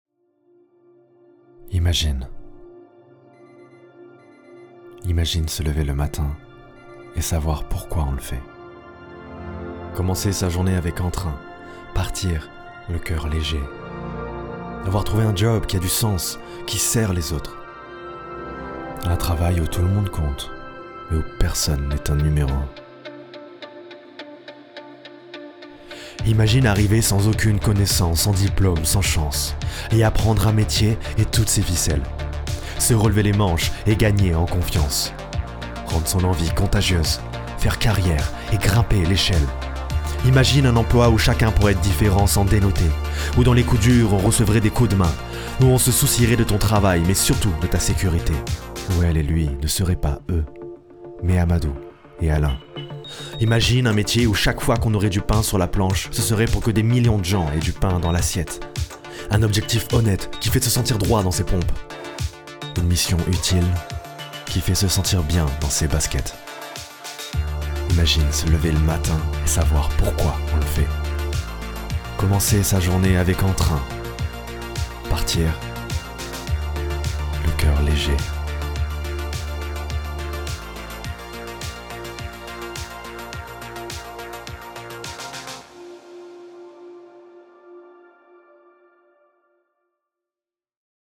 Maquette pour publicité "STEF"
Voix off
25 - 40 ans - Ténor